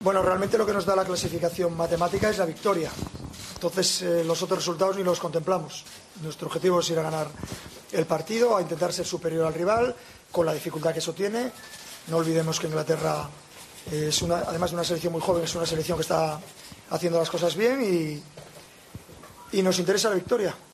El técnico asturiano, en la sala de prensa del estadio bético antes del entrenamiento programado a puerta abierta, precisó que, pese a que un punto más daría el pase de manera virtual, tendrán "muchas dificultades ante Inglaterra, una selección joven y que hace las cosas bien".